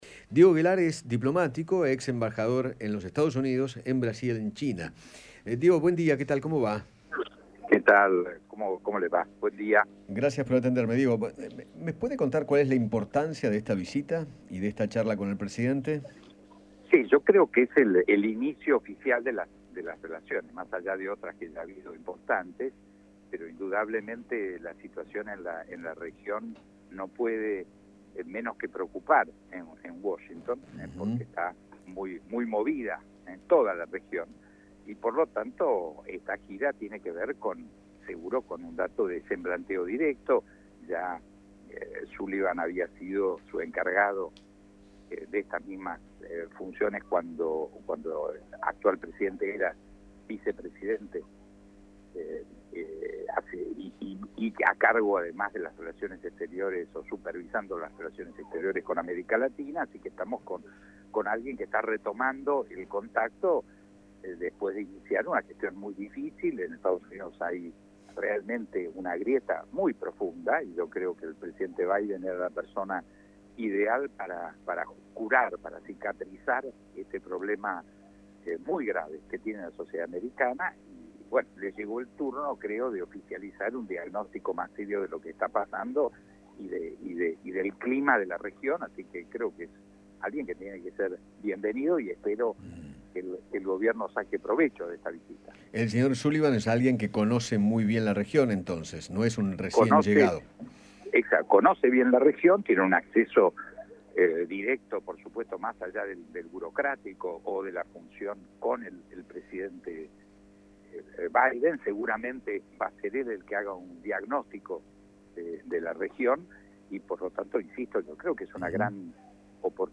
Diego Guelar, ex embajador en China, conversó con Eduardo Feinmann sobre la visita del asesor de Seguridad Nacional de los Estados Unidos al país y se refirió a la reunión que mantendrá con el Presidente.